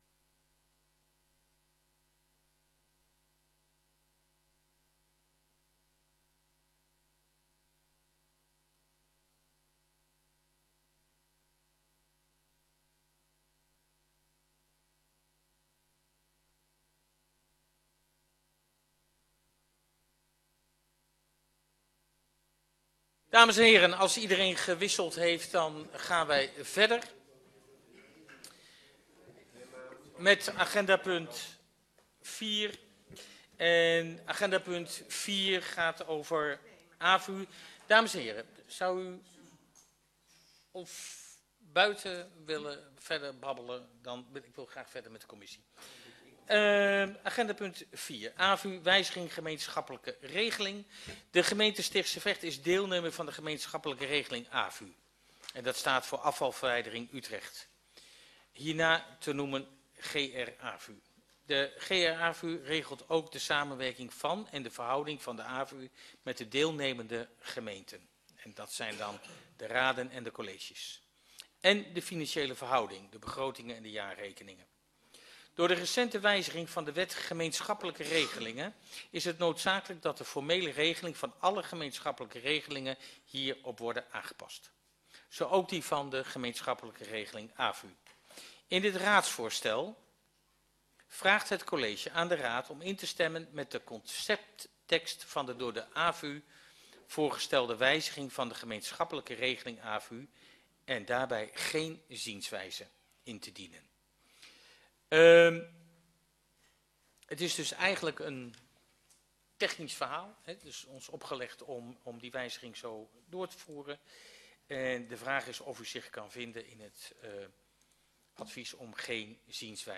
Locatie: Boom en Bosch, Maarssenzaal, Markt 13 3621 AB Breukelen